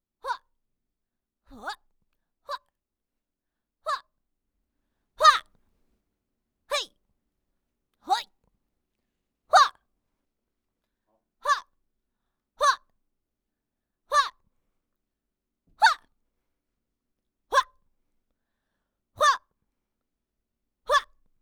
人声采集素材/女激励/嚯.wav